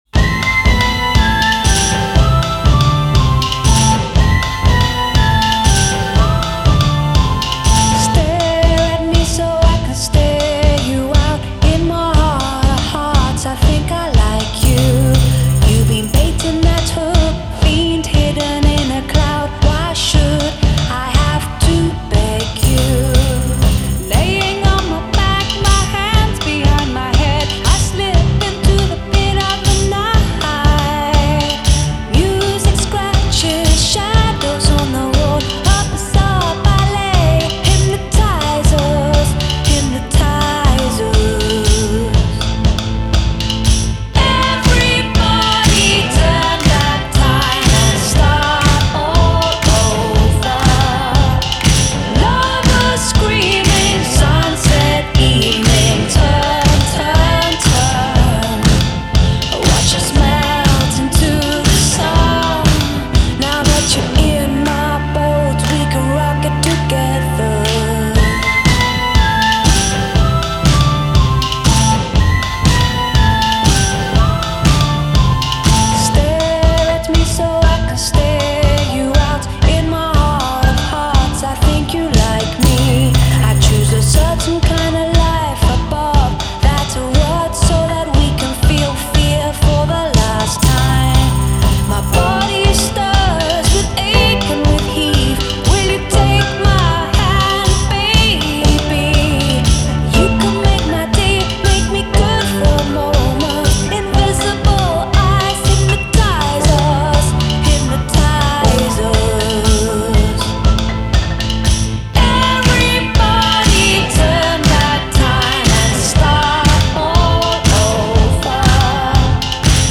régalez-vous de cette pop inventive